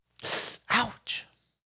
Voice_Ouch_2.wav